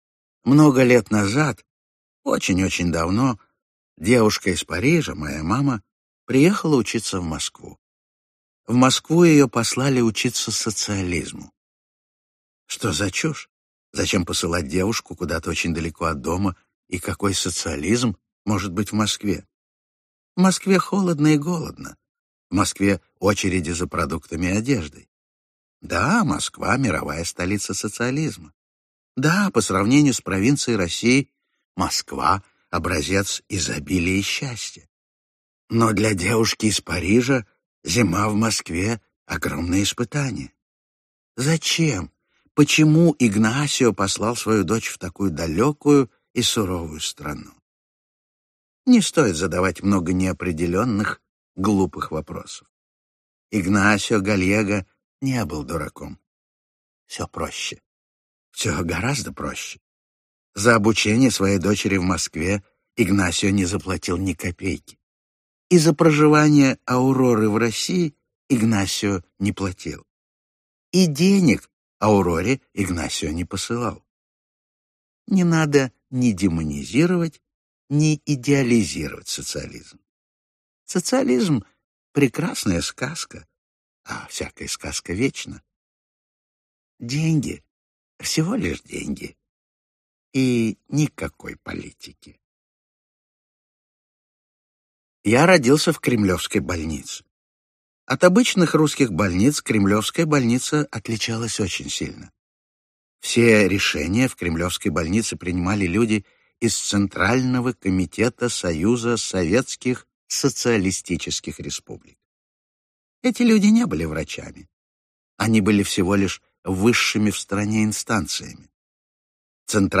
Аудиокнига Вечный гость | Библиотека аудиокниг
Aудиокнига Вечный гость Автор Рубен Давид Гонсалес Гальего Читает аудиокнигу Ефим Шифрин.